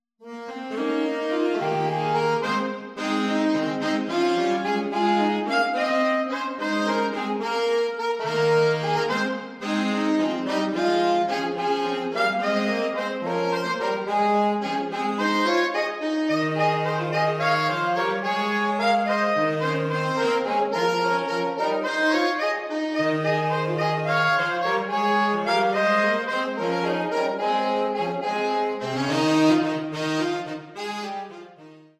Saxophone Trio
3 Jazzy Saxophone Trios based on Scottish Folk Songs